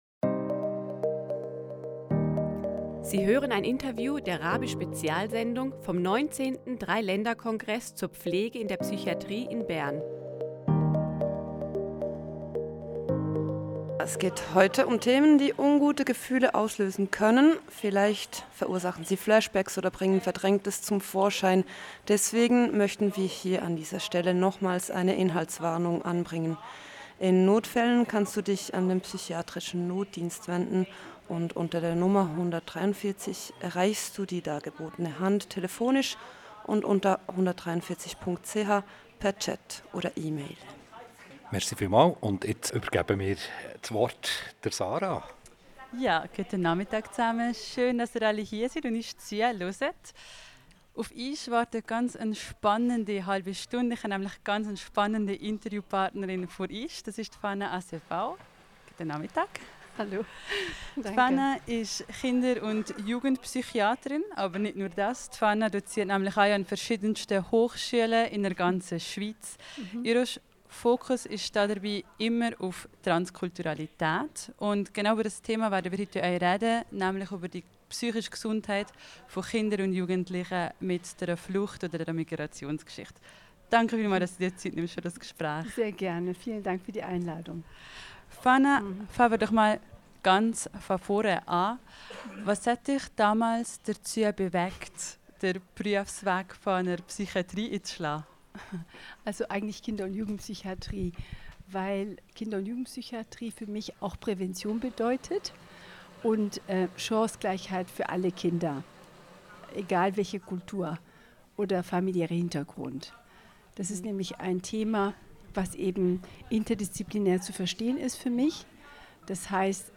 Im Interview spricht sie über die psychische Gesundheit von Kinder und Jugendlichen mir Fluchterfahrung und neuen möglichst leicht zugänglichen Unterstützungsangeboten wie Brückenbauer*innen und Trauma.